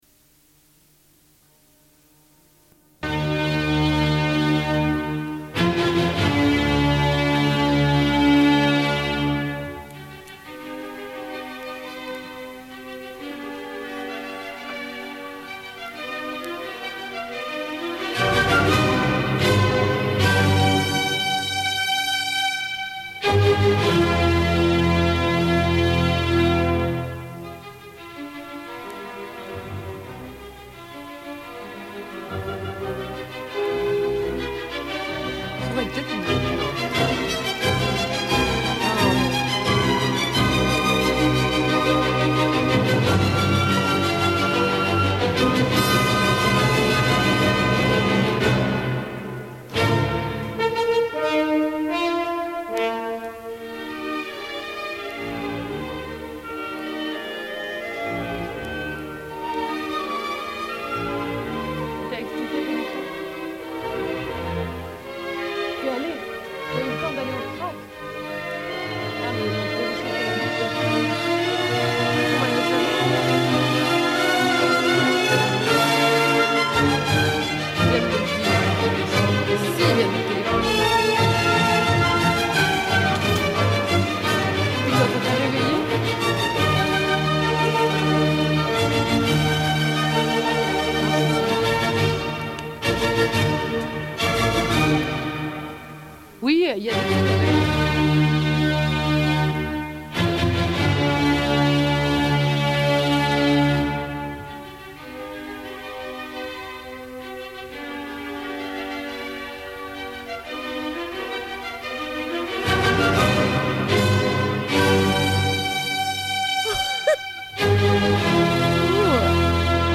Une cassette audio, face A44:17